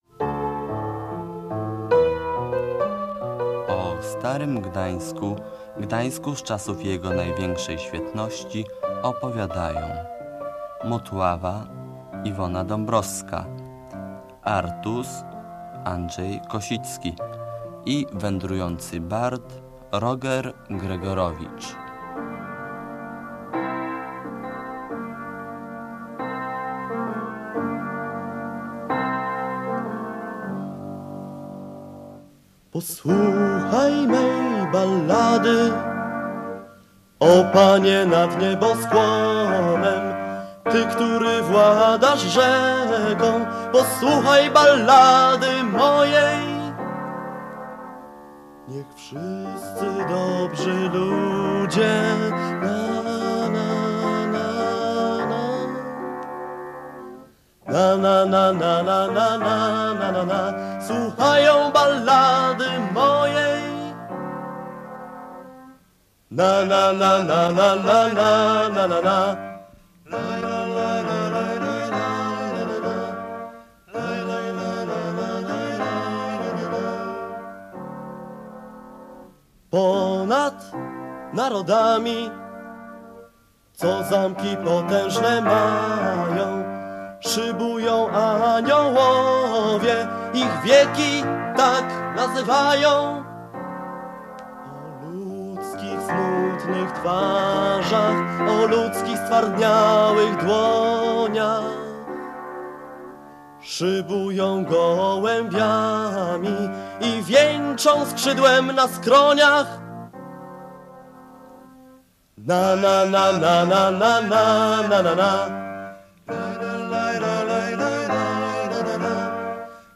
Historia XVI-wiecznego Gdańska opowiadana przez Motławę, Artusa i Wędrującego Barda. Adaptacja radiowa sztuki Teatru ETAT (Estrada Teatralna Akademików Trójmiasta)